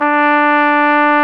Index of /90_sSampleCDs/Roland LCDP12 Solo Brass/BRS_Flugelhorn/BRS_Flugelhorn 1